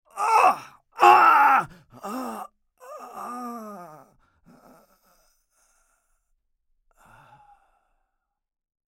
В подборке – резкие вскрики, предсмертные стоны и другие жуткие эффекты длиной от 1 до 16 секунд.
Звук человеческой боли для монтажа